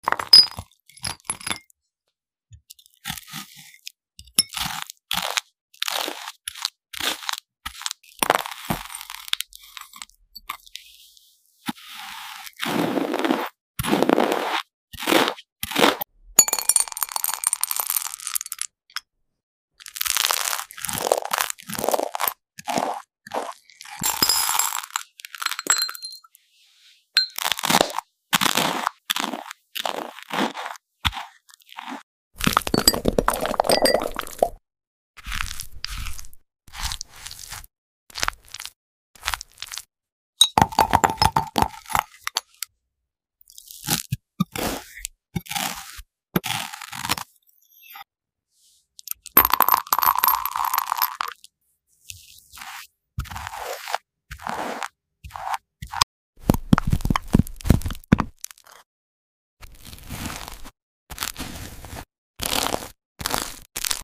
Spreading Kiwi On Toast ASMR Sound Effects Free Download